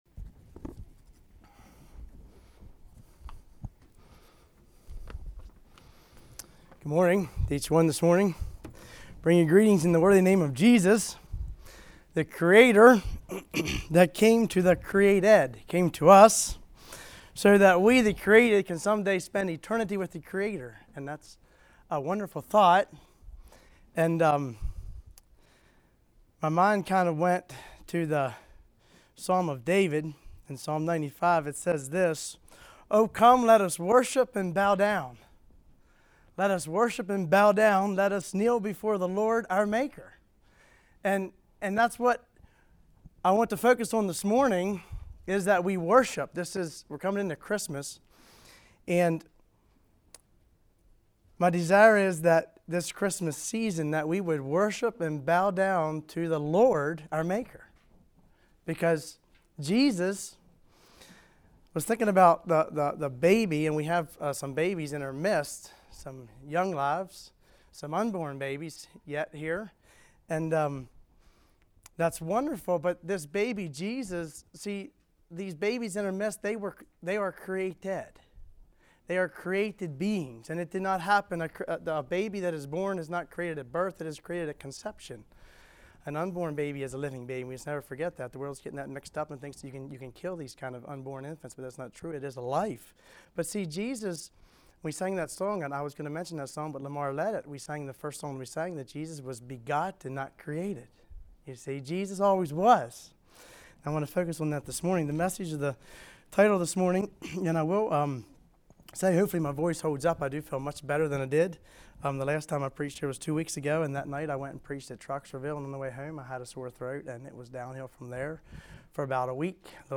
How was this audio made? Altoona | Bible Conference 2024